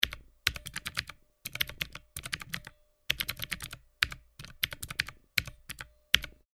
キーボード | 無料 BGM・効果音のフリー音源素材 | Springin’ Sound Stock
タイピング-メカニカル長1.mp3